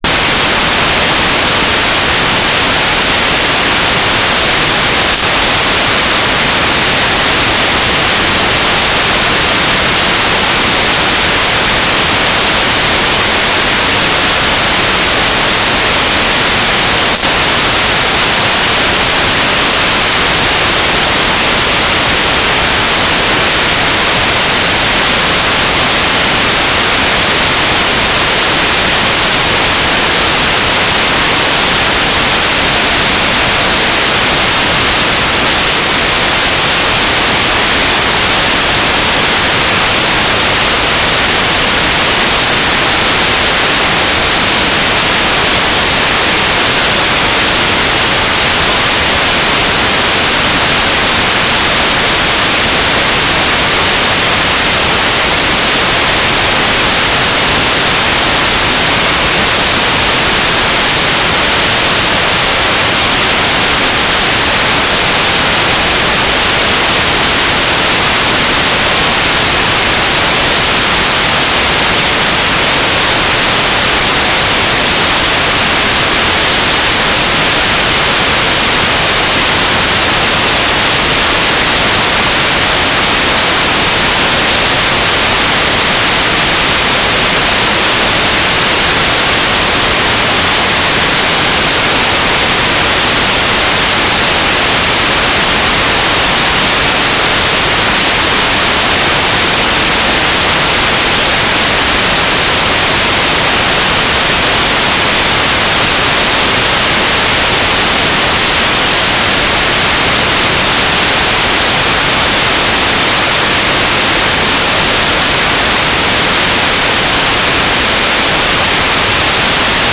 "transmitter_description": "Mode U - GMSK2k4 - USP",